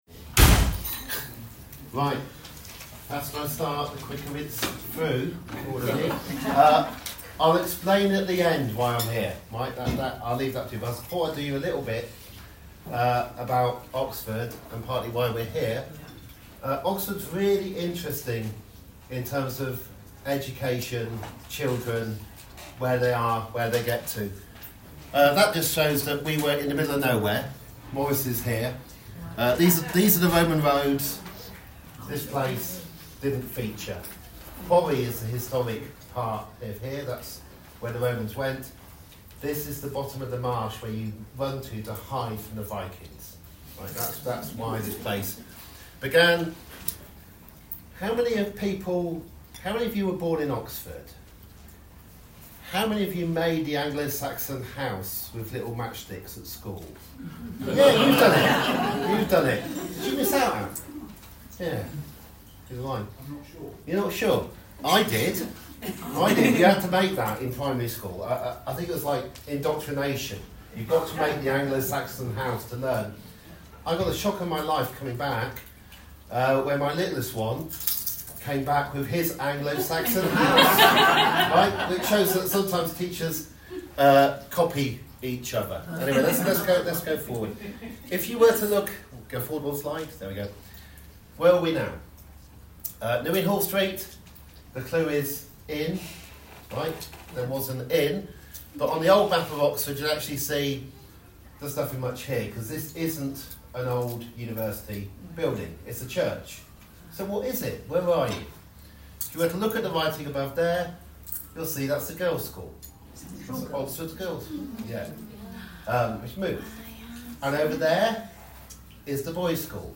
This celebration was held at St Peter's Collge Oxford, on Friday 21st June 2024. The recording is just of Danny Dorling's contribution.